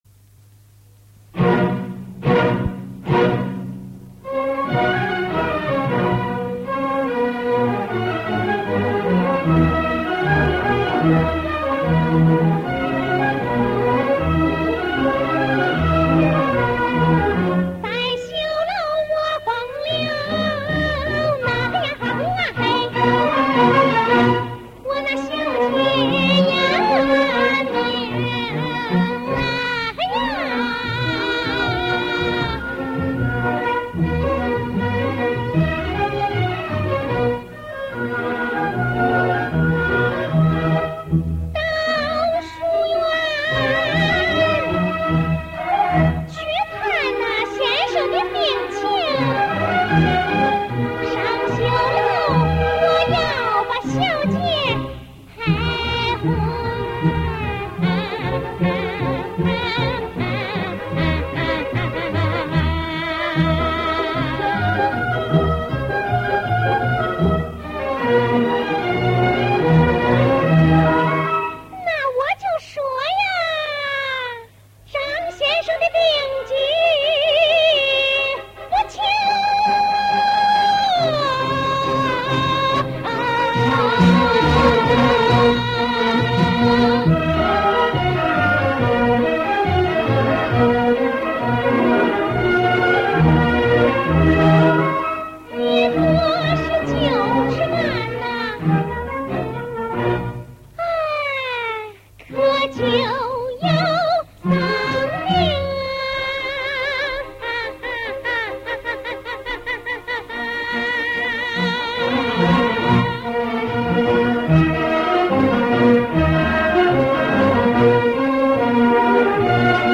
[11/8/2010]郑绪兰演唱的豫剧《拷红》片断=上绣楼我要把小姐赫哄 激动社区，陪你一起慢慢变老！